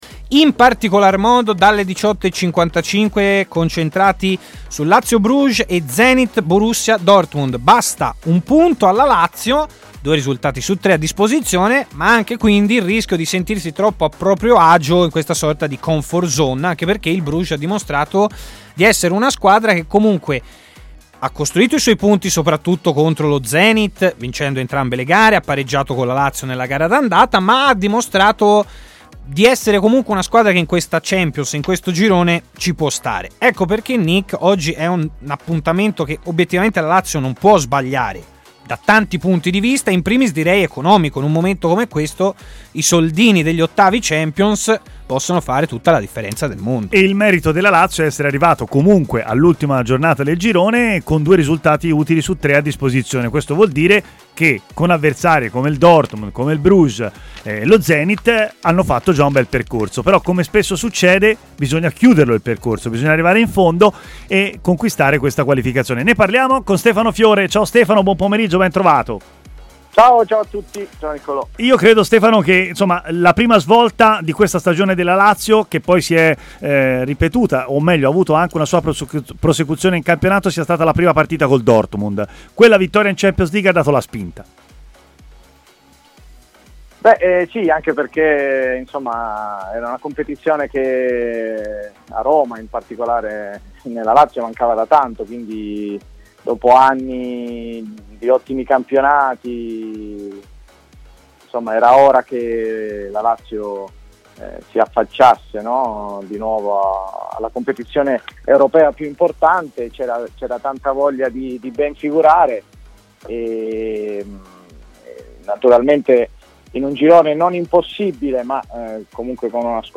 L'ex centrocampista Stefano Fiore è intervenuto in diretta a Stadio Aperto, trasmissione di TMW Radio